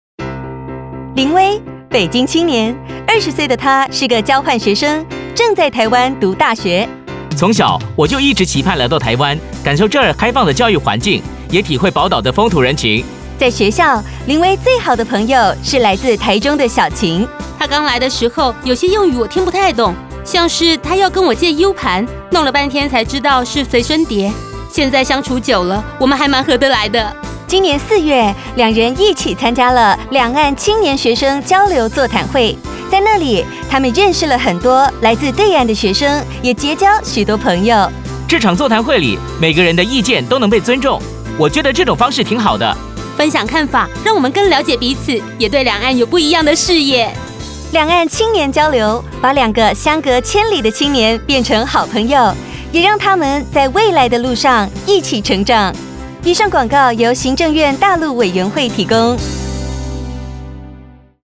台語配音 國語配音 女性配音員